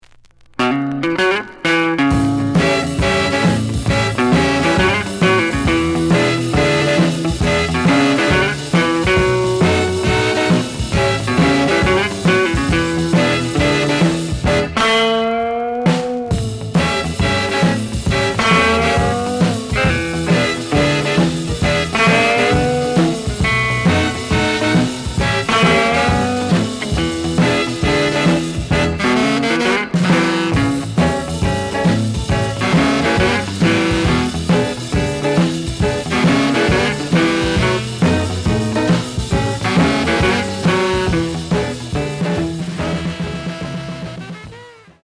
試聴は実際のレコードから録音しています。
The listen sample is recorded from the actual item.
●Genre: Rhythm And Blues / Rock 'n' Roll